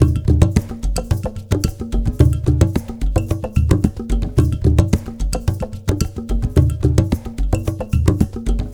APH ETHNO2-L.wav